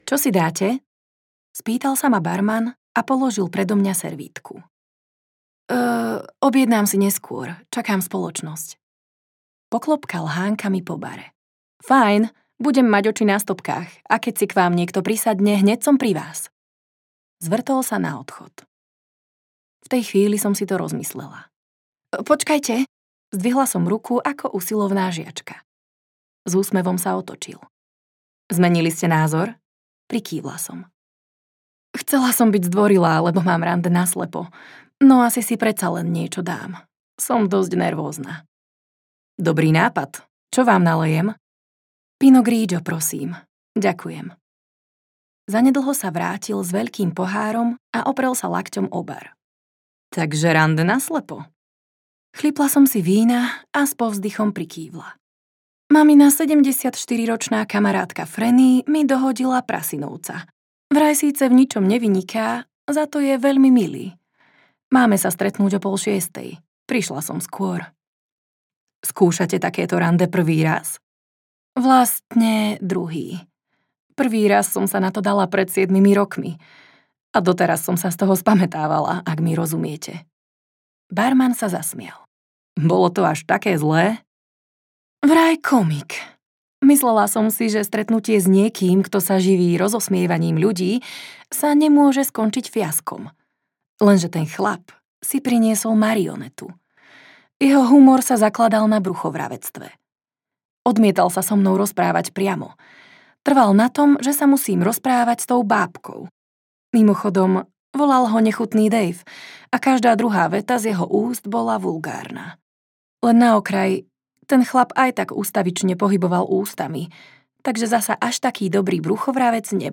Letný návrh audiokniha
Ukázka z knihy